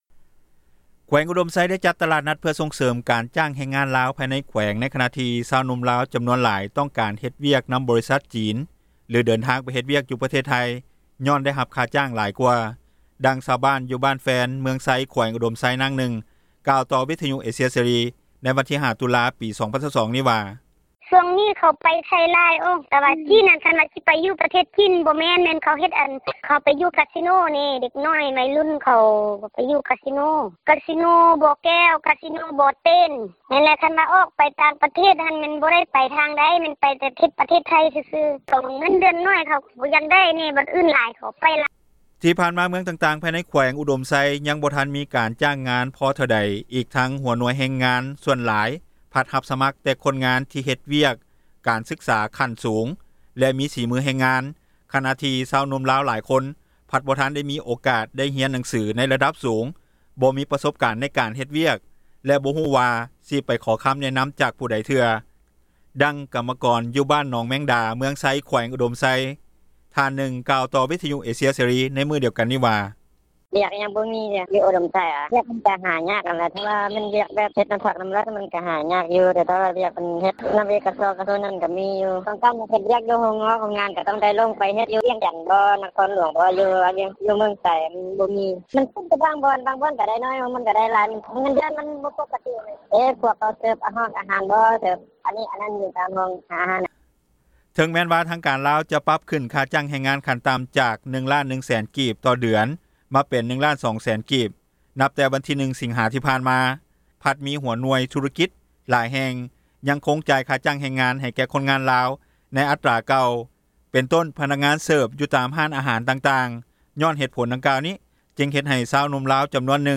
ດັ່ງຊາວບ້ານ ຢູ່ບ້ານແຟນ ເມືອງໄຊ ແຂວງອຸດົມໄຊ ນາງນຶ່ງກ່າວຕໍ່ວິທຍຸ ເອເຊັຽເສຣີ ເມື່ອວັນທີ 05 ຕຸລາ 2022 ນີ້ວ່າ:
ດັ່ງແມ່ຄ້າຂາຍເຄື່ອງຍົກ, ເຄື່ອງຫຍ່ອຍ ຢູ່ບ້ານແຟນ ເມືອງໄຊ ແຂວງອຸດົມໄຊ ນາງນຶ່ງ ກ່າວວ່າ: